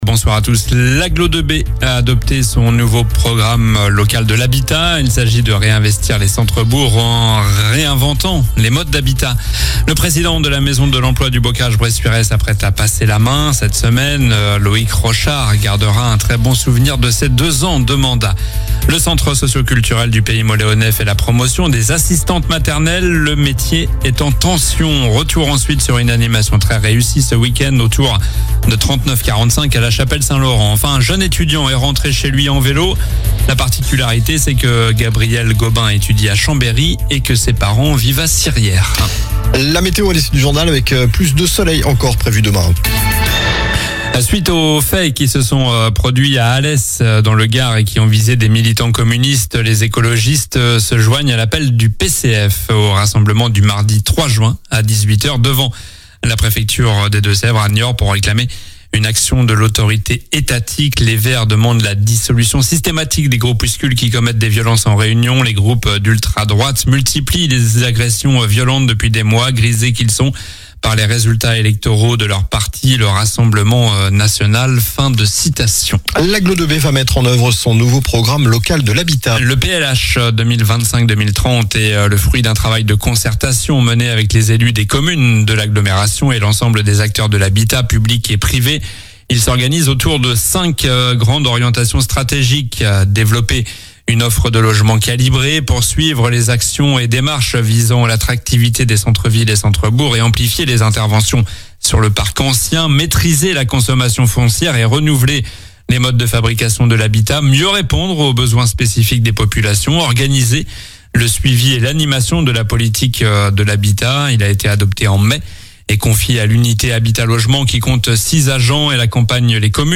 Journal du lundi 02 juin (soir)